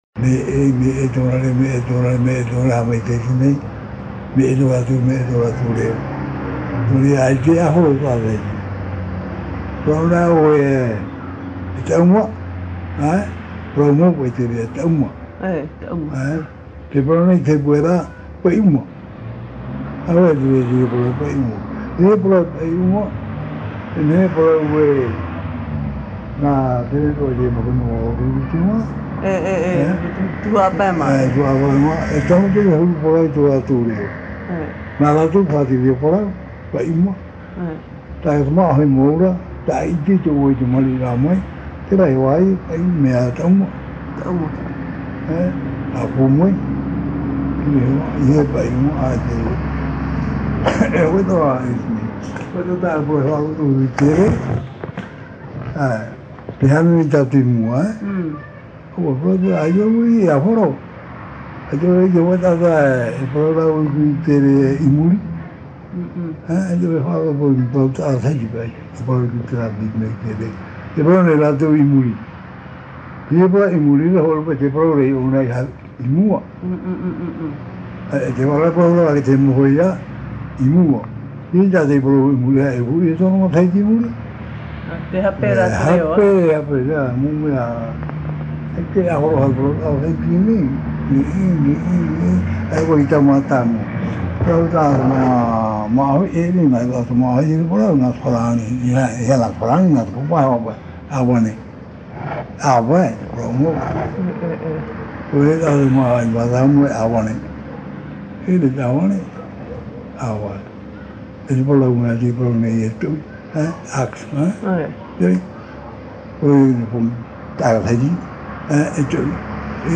Interview réalisée à Pape'ete sur l’île de Tahiti.
Papa mātāmua / Support original : cassette audio